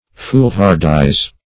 Search Result for " foolhardise" : The Collaborative International Dictionary of English v.0.48: Foolhardise \Fool"har`dise\, n. [Fool, F. fol, fou + F. hardiesse boldness.]
foolhardise.mp3